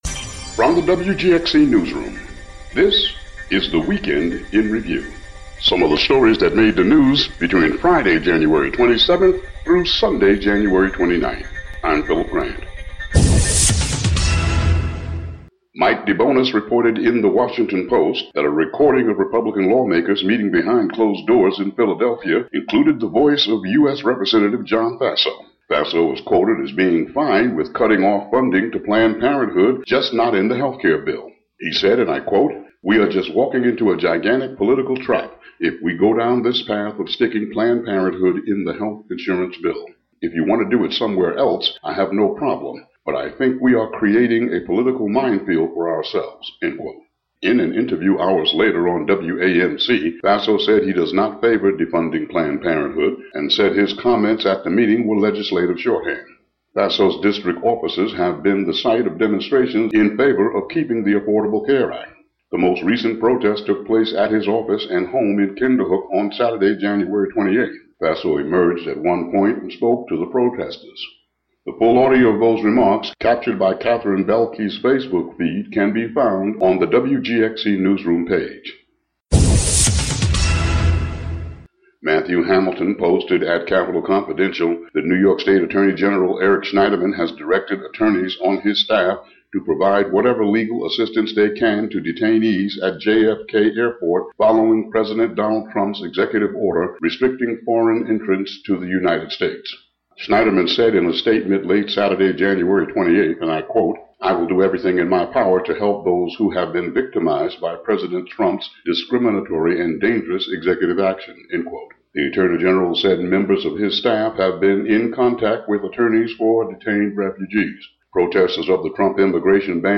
WGXC daily headlines for Jan. 30, 2017.